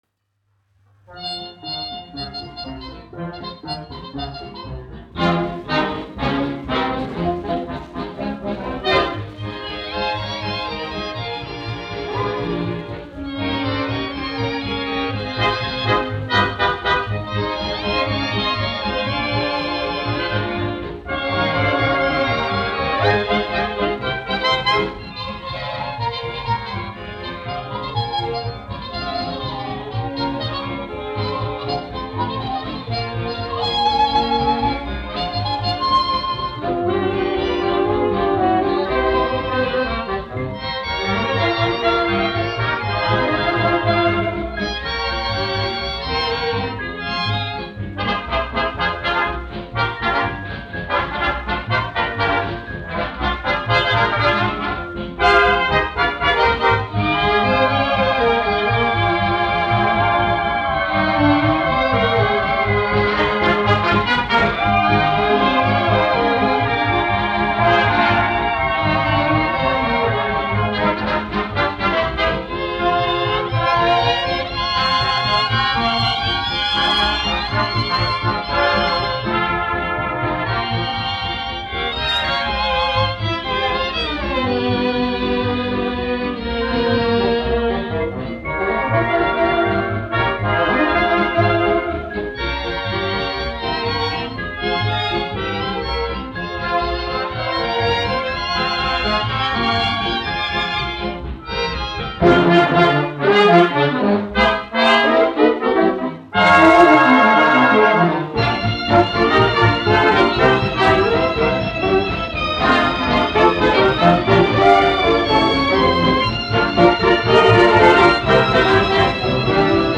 1 skpl. : analogs, 78 apgr/min, mono ; 25 cm
Populārā instrumentālā mūzika
Džezs
Skaņuplate